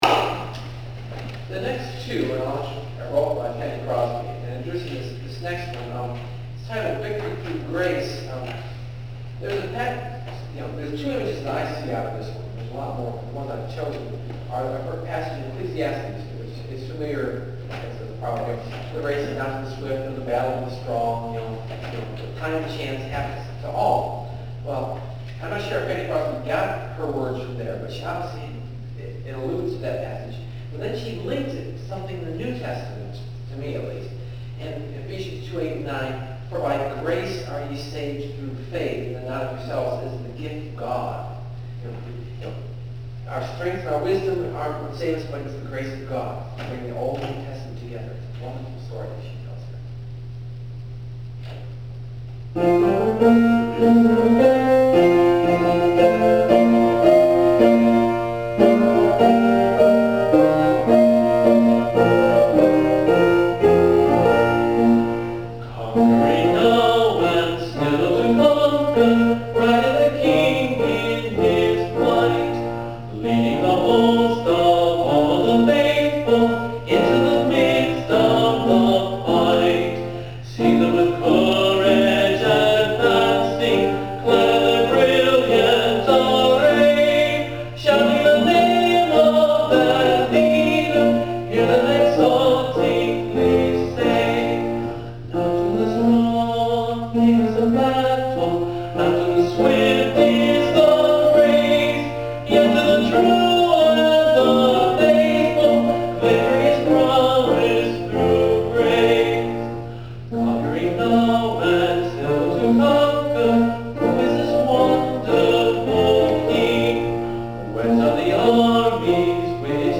(Part of a series of blogs on the songs featured in my solo concert from 2006: “A Concert of Sacred Metaphors”)